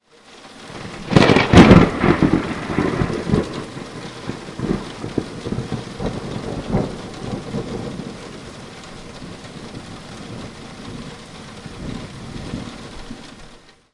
雷霆，非常接近，雨，A
描述：接近的雷罢工的未加工的音频与雨的在背景中。
声音在2016年9月15日使用“H1 Zoom录音机”录制。
标签： 罢工 雷声 闪电 雷暴 关闭 关闭 雷电 暴雨 天气 罢工 非常 雷电 暴雨 雷暴 迅雷 天气 雷霆 非常风暴
声道立体声